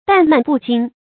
誕謾不經 注音： ㄉㄢˋ ㄇㄢˋ ㄅㄨˋ ㄐㄧㄥ 讀音讀法： 意思解釋： 荒誕虛妄，不合常理。